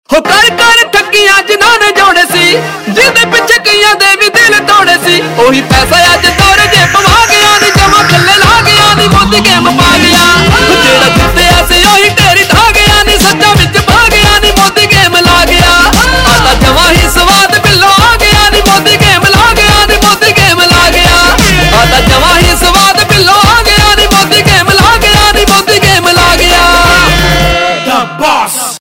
Punjabi Mp3 Tone